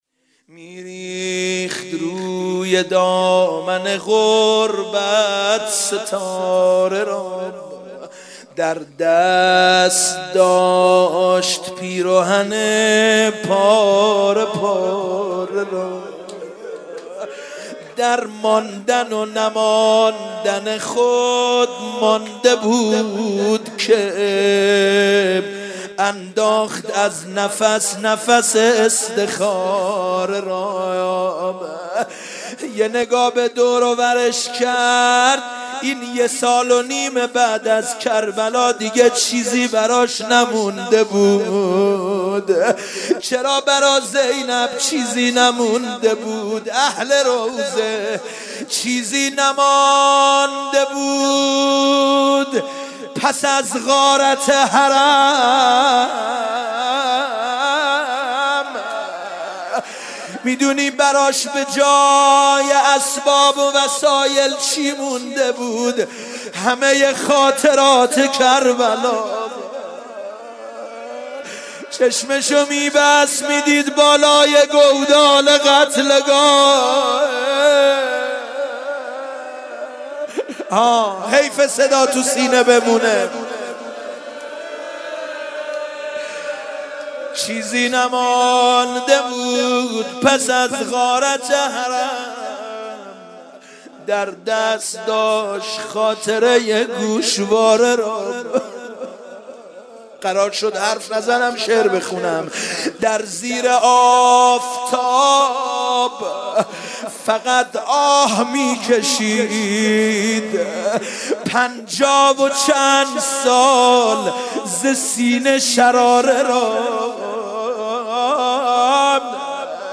سینه زنی جهاد